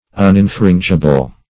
Search Result for " uninfringible" : The Collaborative International Dictionary of English v.0.48: Uninfringible \Un`in*frin"gi*ble\, a. That may not be infringed; as, an uninfringible monopoly.